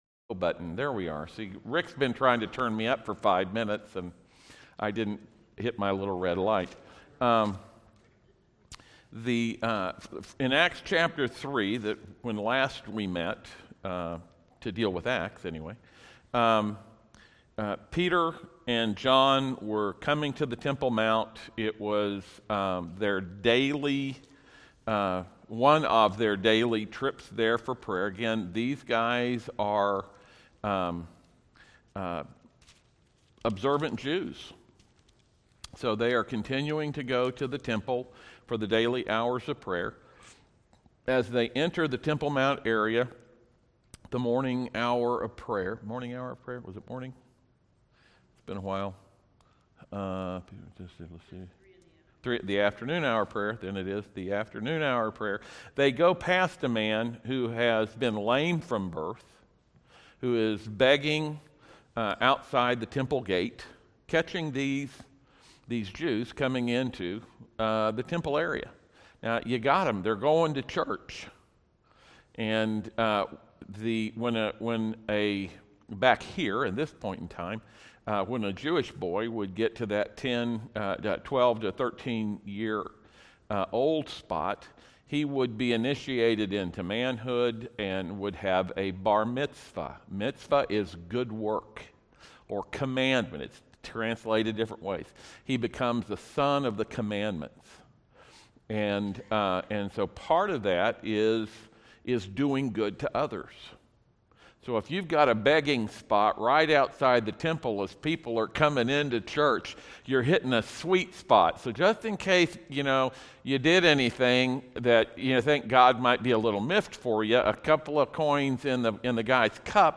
Sermon-Audio-for-October-15th-PM.mp3